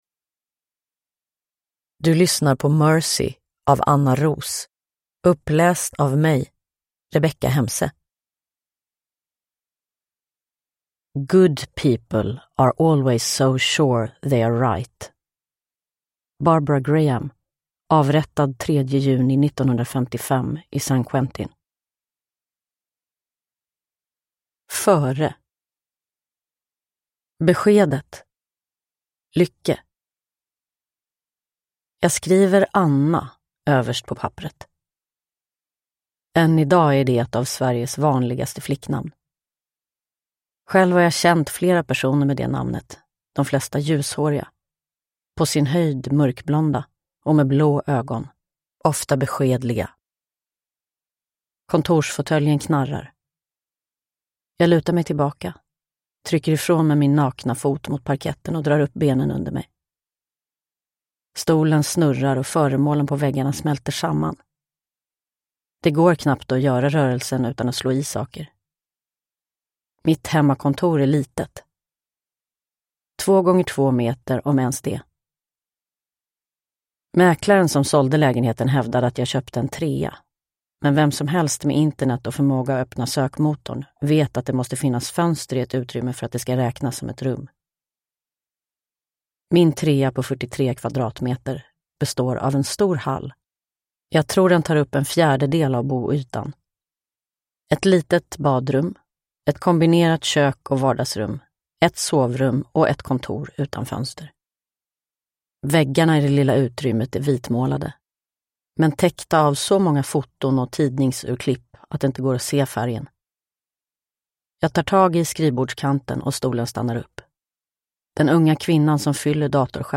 Uppläsare: Rebecka Hemse
Ljudbok